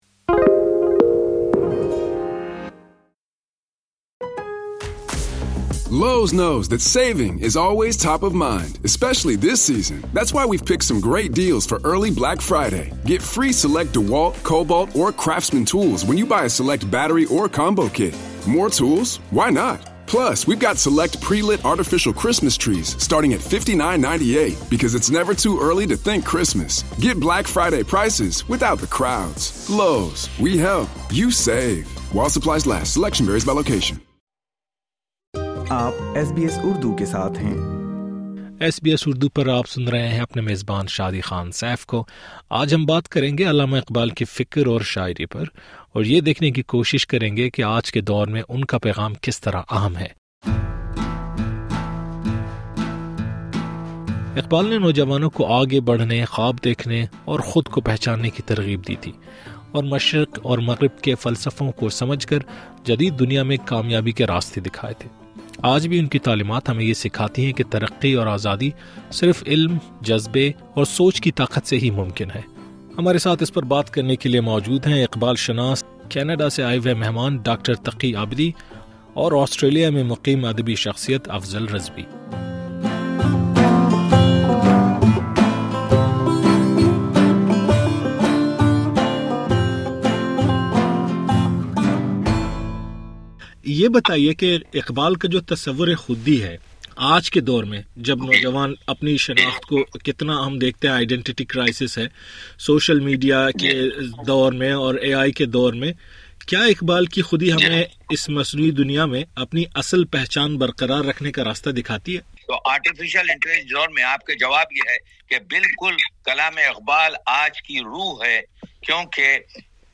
اقبال اور آج کا نوجوان — آسٹریلیا سے ایک فکری گفتگو